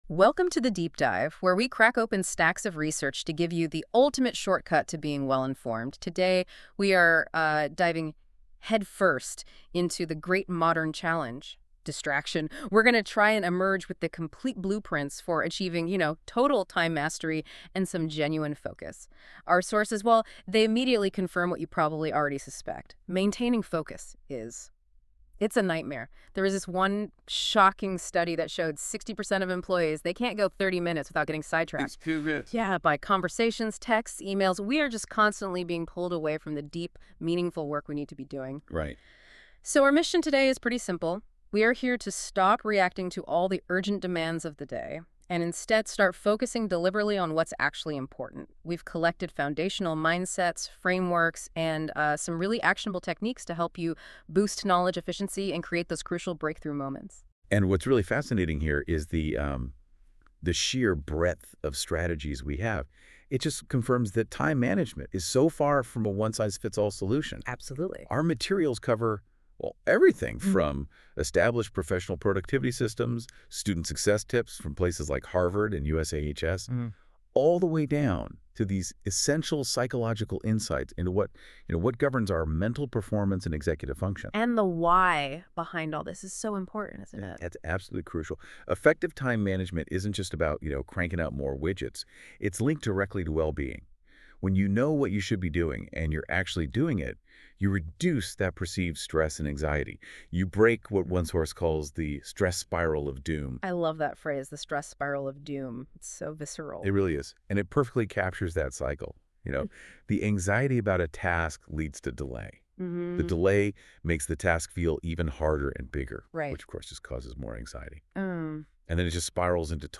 Activity: Listen to This Podcast That was created using AI from these materials.